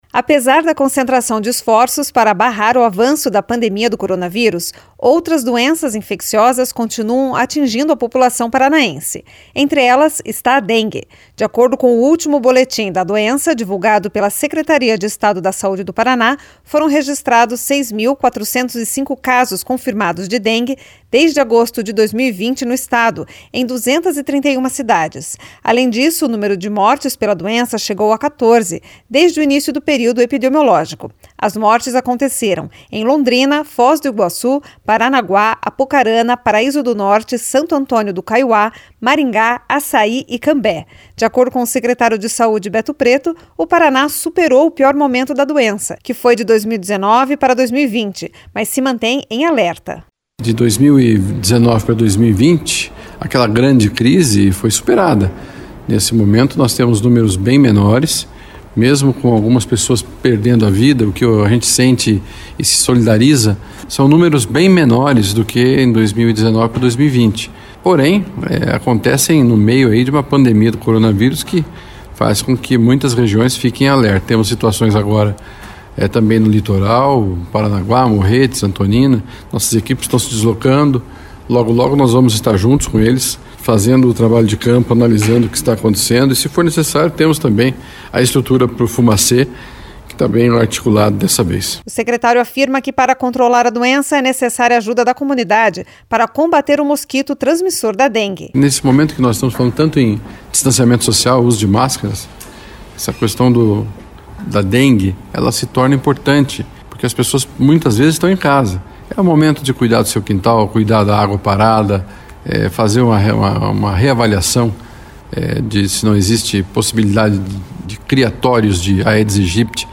De acordo com o secretário de Saúde, Beto Preto, o Paraná superou o pior momento da doença, que foi de 2019 para 2020, mas se mantém em alerta.